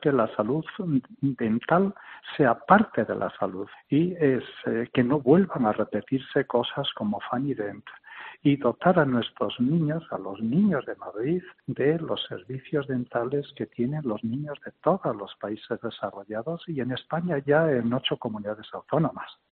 José Manuel Freire, portavoz de Sanidad del Grupo Socialista de la Asamblea de Madrid